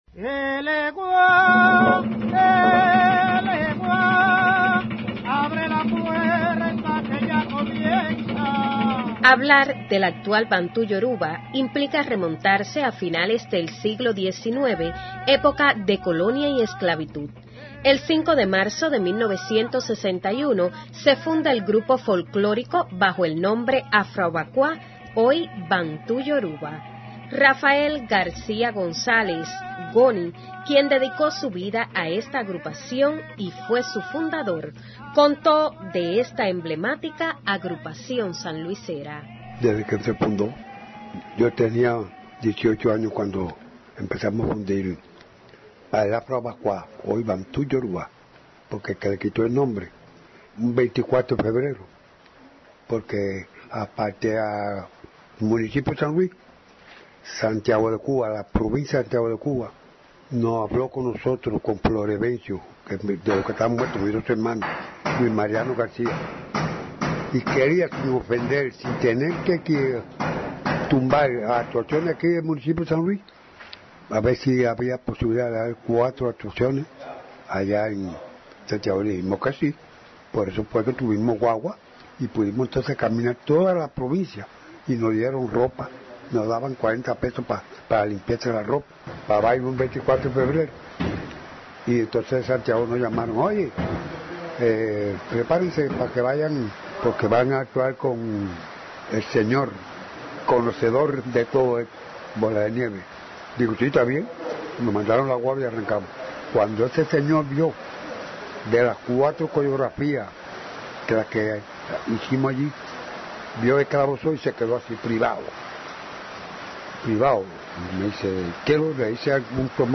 (PROGRAMA EN COMPETENCIA. FESTIVAL MUNICIPAL DE LA RADIO)